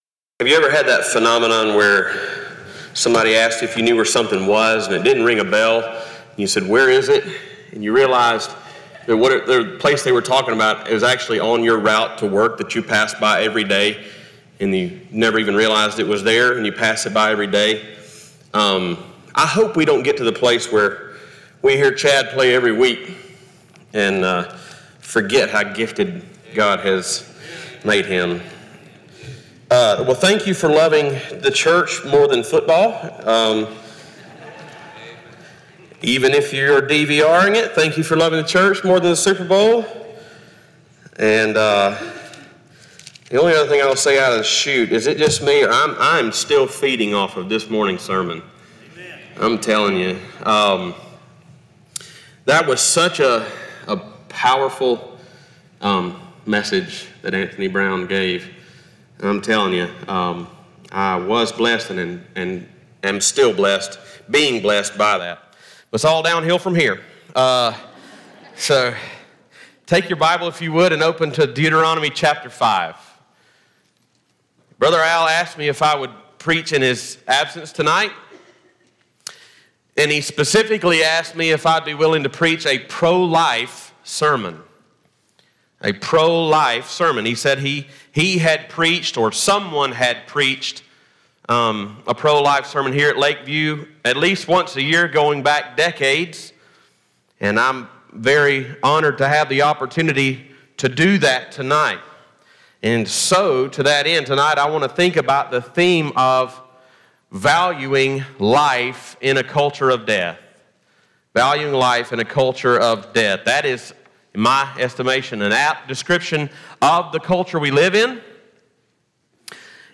Series: Stand Alone Sermons
Deuteronomy 5:17 Service Type: Sunday Evening "Pro Life" is